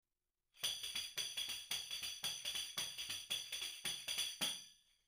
Escóitao aquí interpretado por unha pandeireta:
pandeireta.mp3